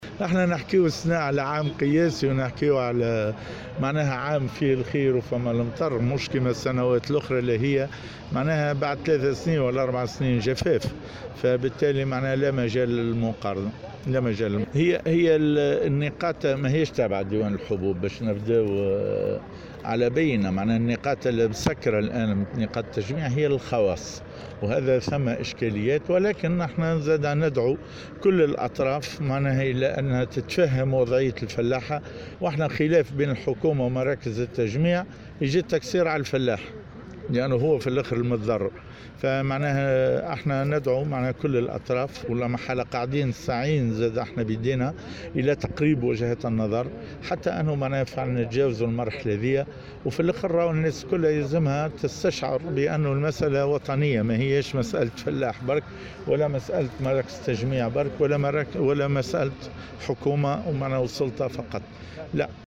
كما أشار رئيس الاتحاد التونسي للفلاحة و الصيد البحري في تصريحه إلى أنّه لا مجال للمقارنة بين صابة هذا الموسم و الموسم الفارط ، واصفا صابة هذا الموسم بالقياسيّة .